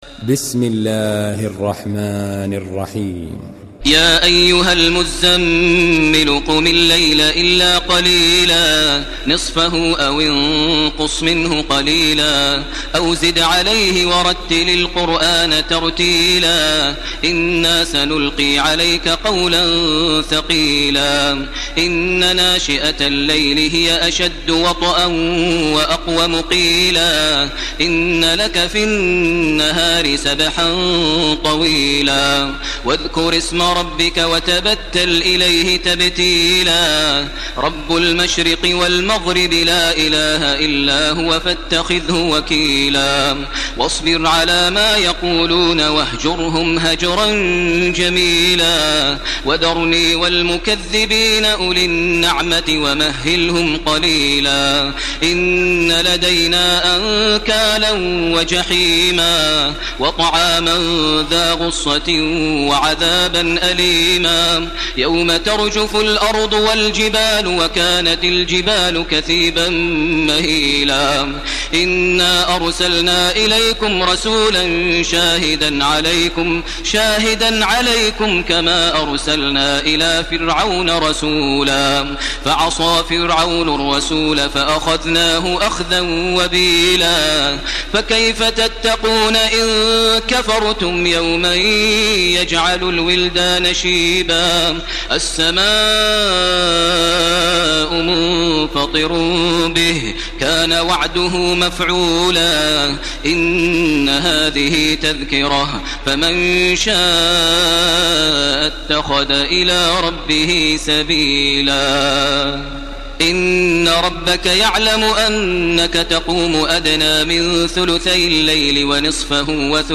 Surah Al-Muzzammil MP3 in the Voice of Makkah Taraweeh 1431 in Hafs Narration
Murattal